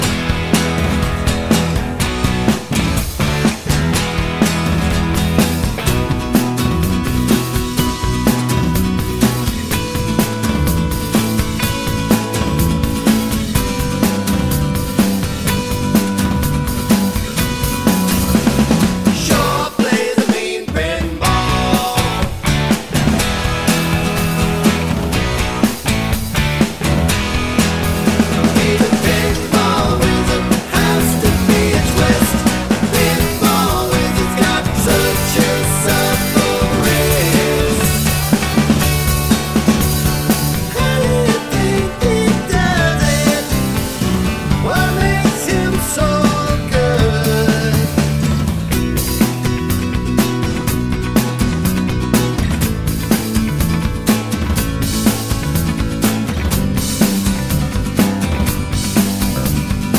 no Backing Vocals Rock 3:08 Buy £1.50